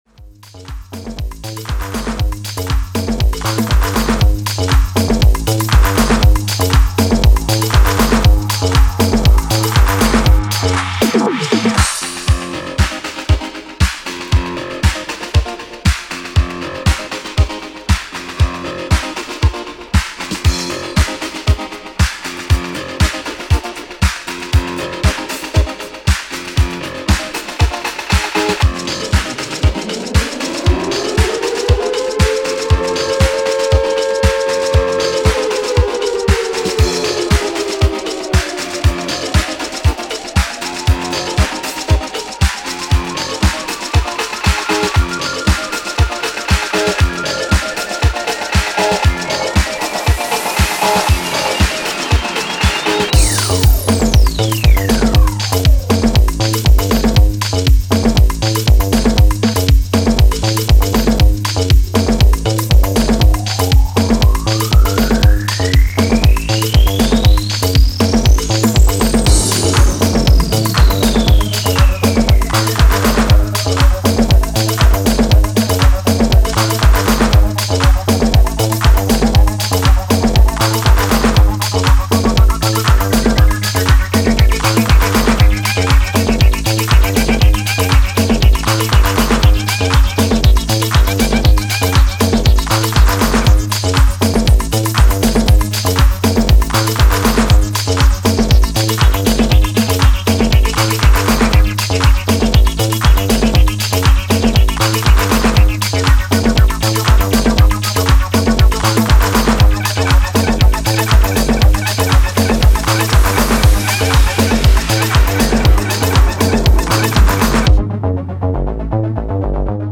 sunset disco joy and eyes-down dirty basement feel...